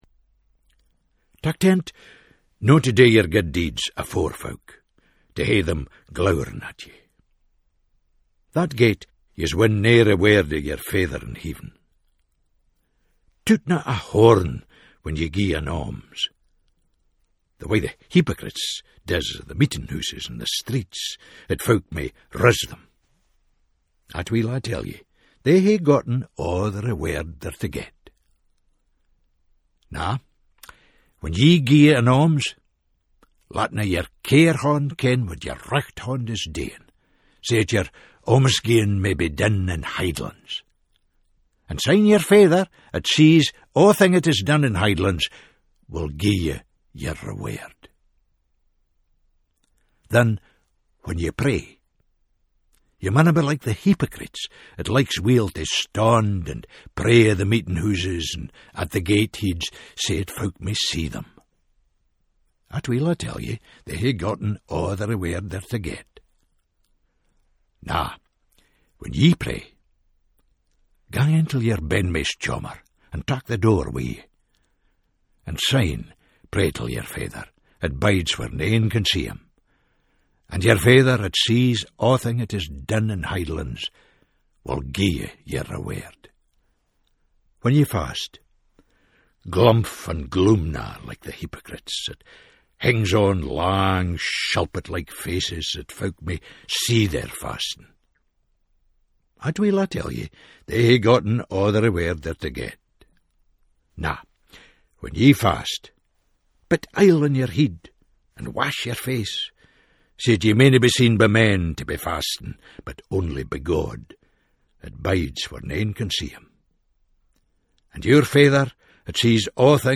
This reading is for Ash Wednesday and is from the Gospel of Matthew Chapter 6:1-6, 16-18.